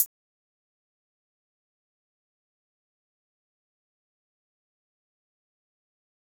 Tm8_HatxPerc58.wav